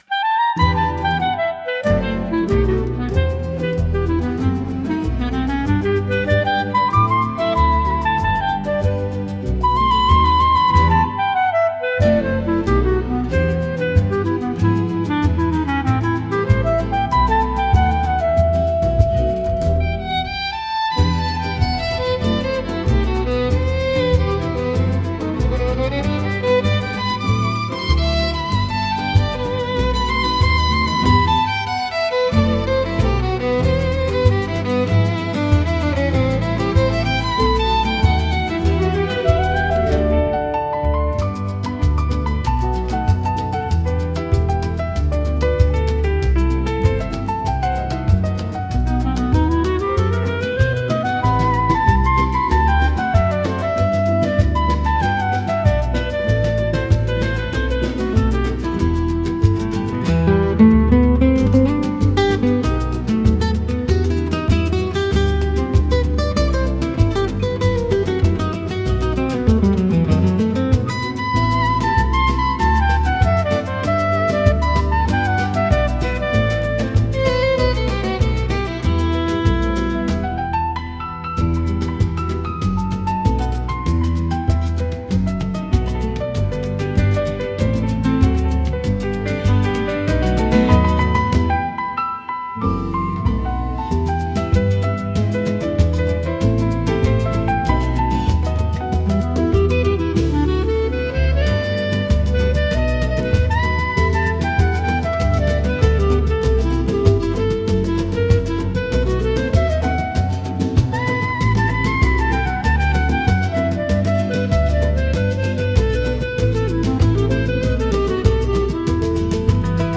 música e arranjo: IA) (Instrumental)